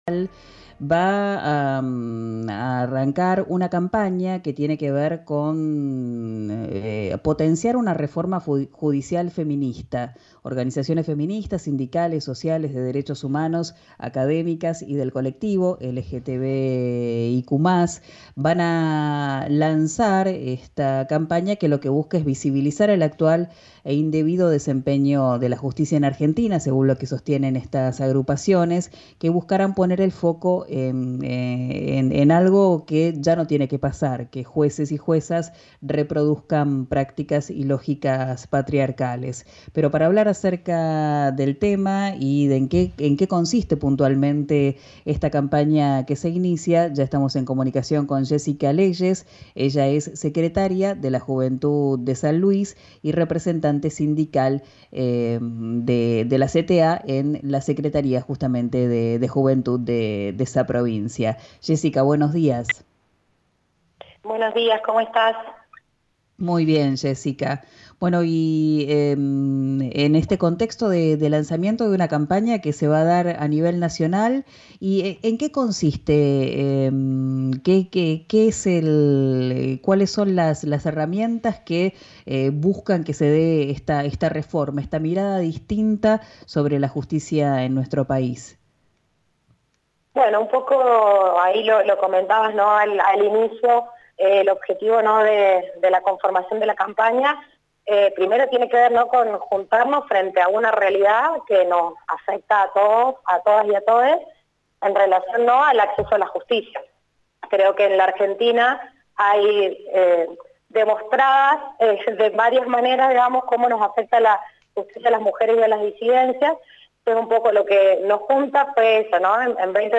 En diálogo con RÍO NEGRO RADIO